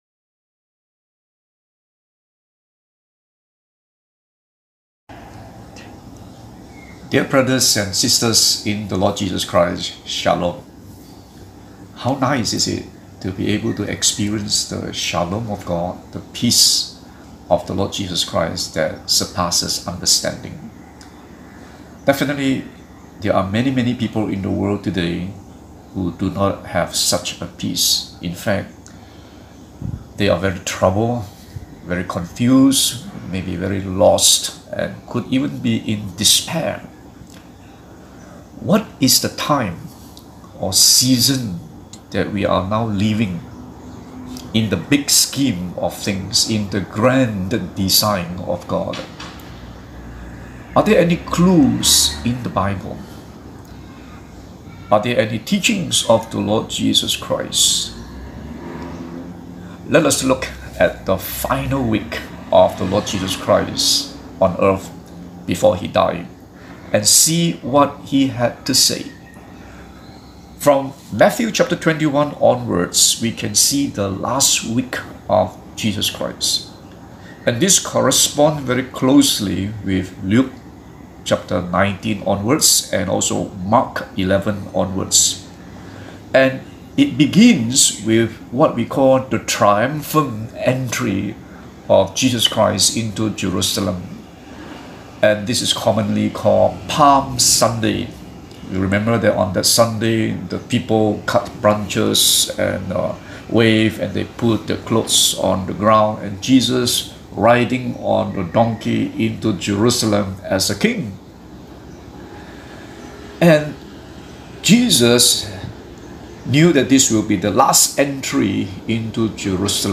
Bible Text: Matthew 24:14 | Preacher